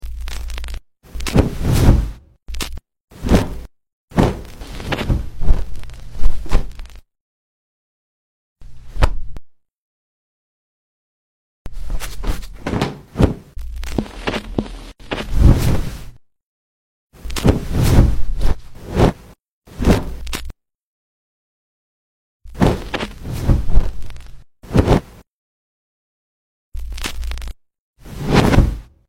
Video overlay/transition with sound fx!